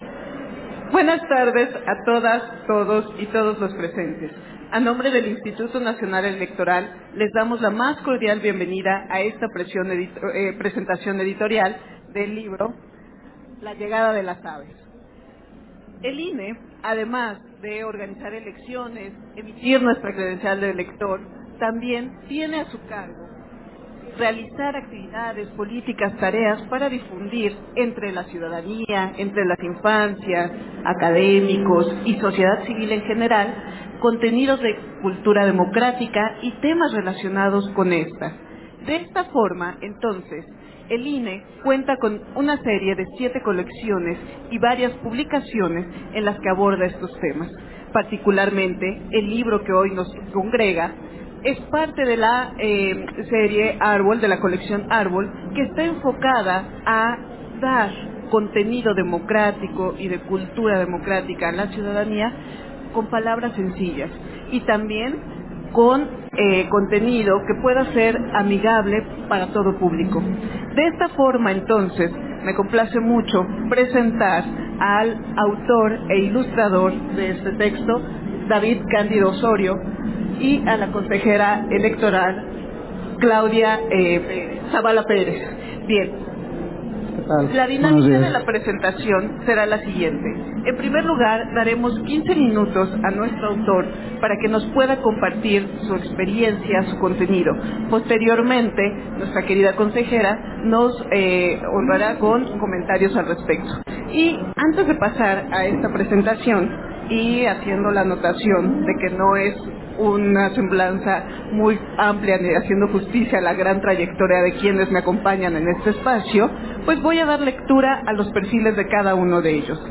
Versión estenográfica de la presentación editorial de la Colección Árbol, La llegada de las aves, en la FIL de Guadalajara 2024